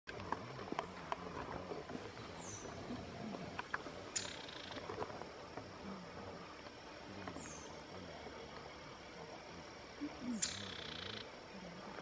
Pichororé (Synallaxis ruficapilla)
Nome em Inglês: Rufous-capped Spinetail
Fase da vida: Adulto
Localidade ou área protegida: Floresta Nacional de São Francisco de Paula
Condição: Selvagem
Certeza: Observado, Gravado Vocal
Synallaxis-ruficapilla.mp3